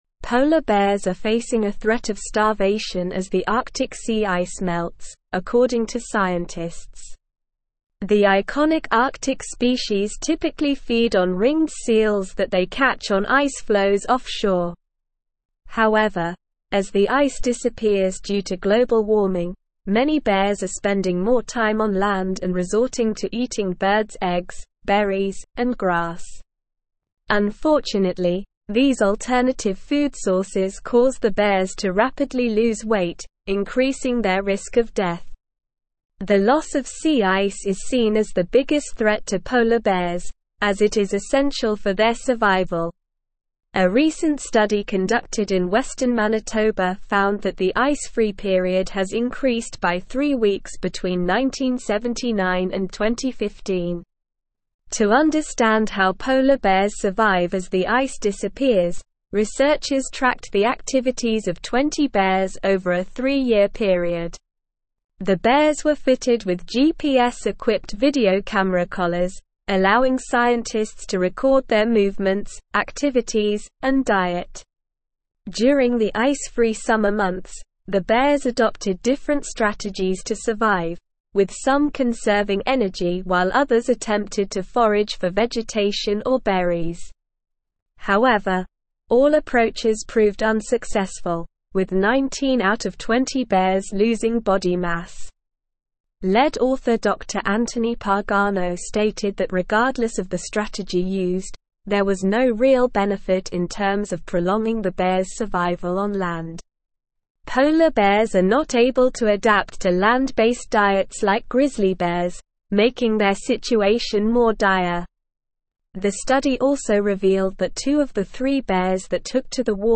Slow
English-Newsroom-Advanced-SLOW-Reading-Polar-bears-face-starvation-as-Arctic-sea-ice-melts.mp3